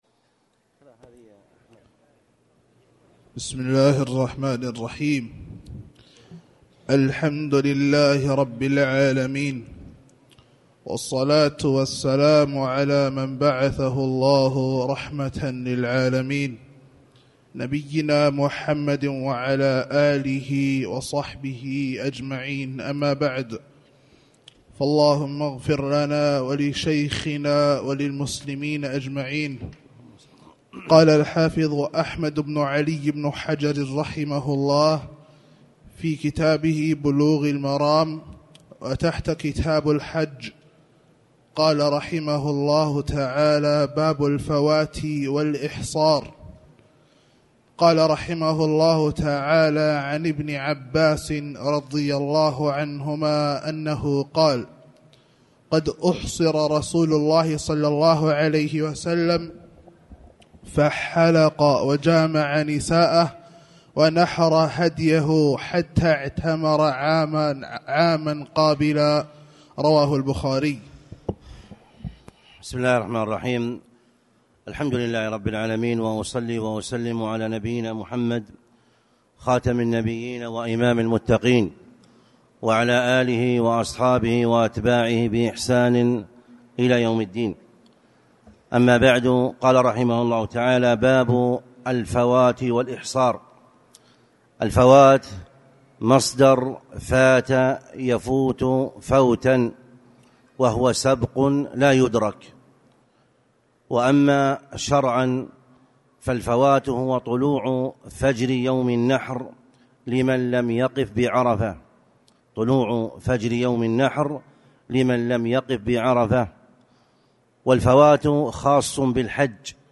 تاريخ النشر ٢٥ محرم ١٤٣٨ هـ المكان: المسجد الحرام الشيخ